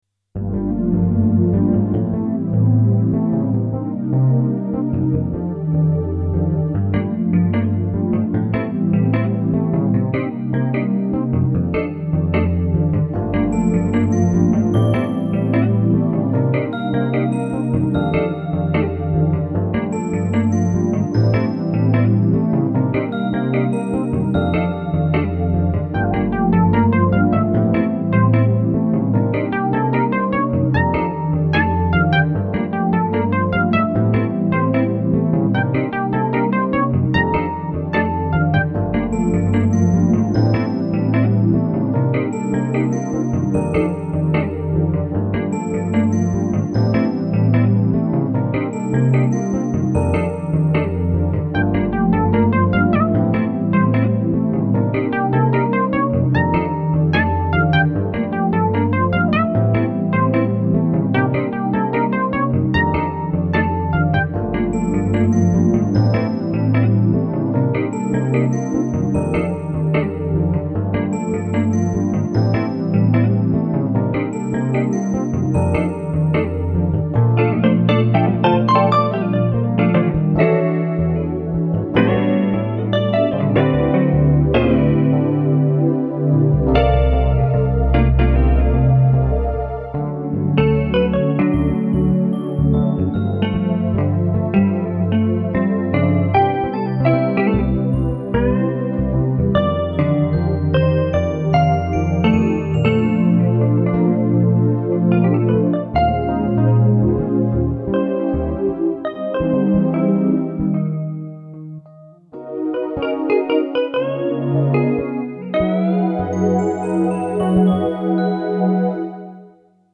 mp3,2818k] Джаз